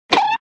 poke.ogg